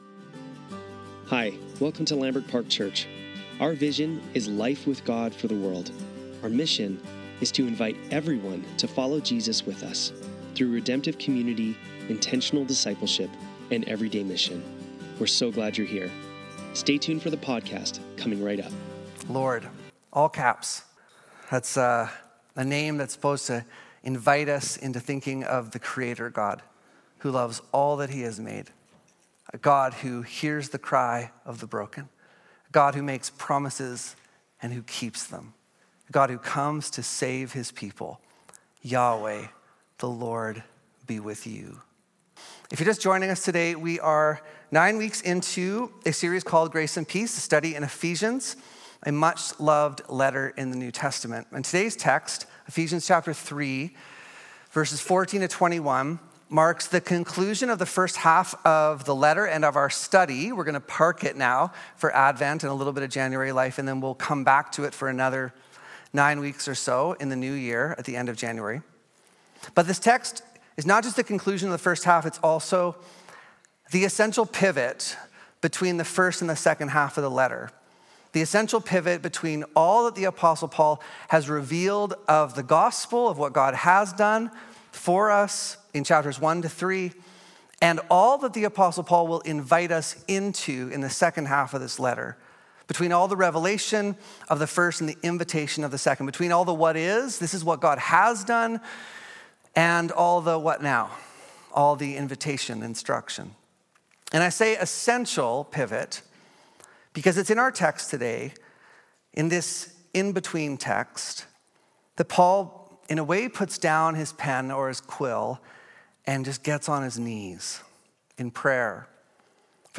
Sunday Service - December 1, 2024